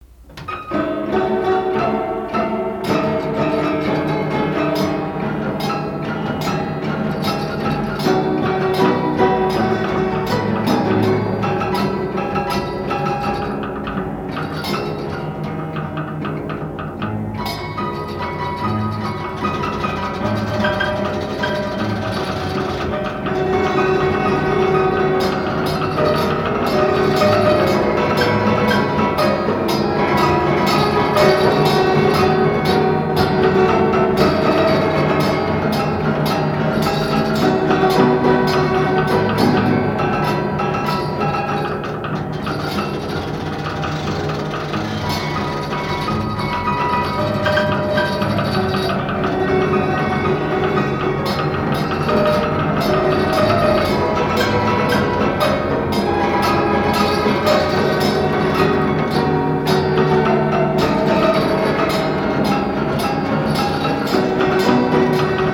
piano mécanique
Pièce musicale inédite